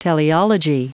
Prononciation du mot teleology en anglais (fichier audio)
Prononciation du mot : teleology
teleology.wav